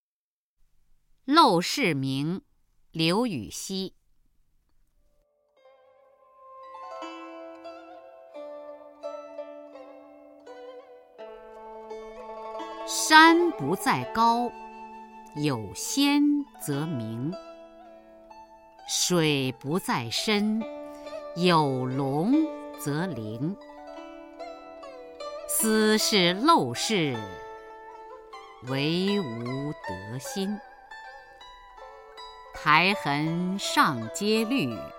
初中生必背古诗文标准朗诵（修订版）（1）-10-雅坤-陋室铭 唐 刘禹锡